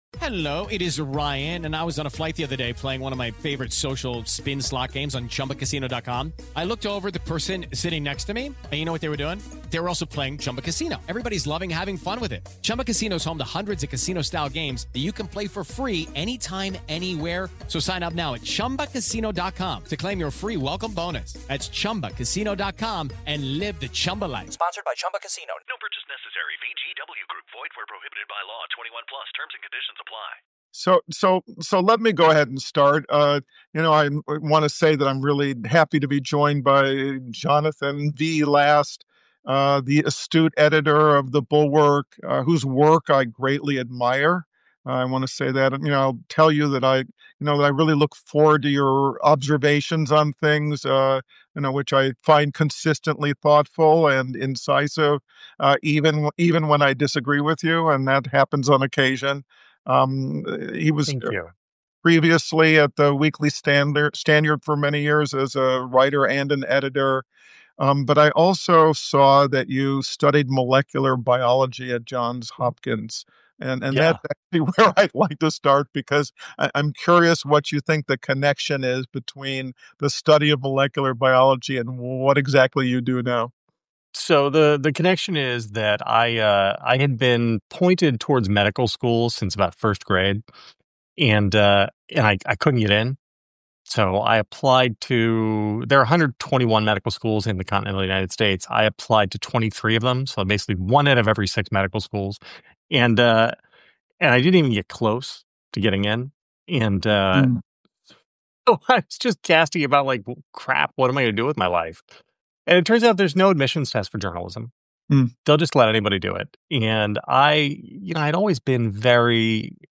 Recorded live on Friday, November 8, 2025, this Substack conversation